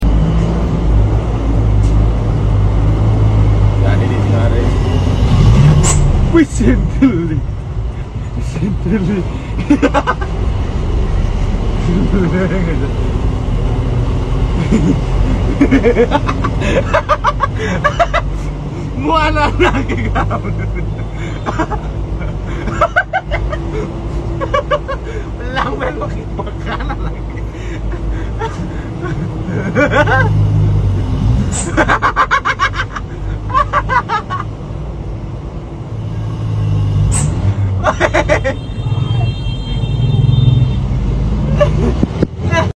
Di Sapa Pake Kentut 🤣 Sound Effects Free Download